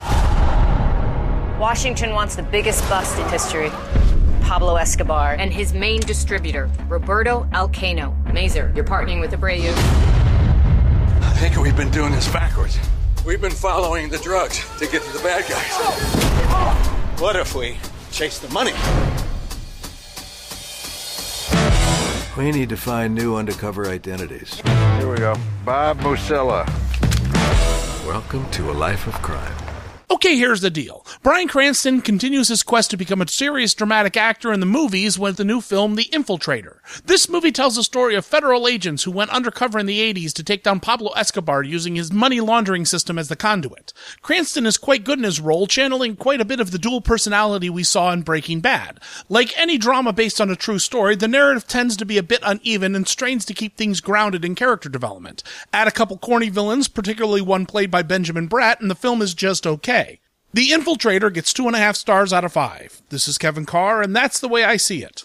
‘The Infiltrator’ Radio Review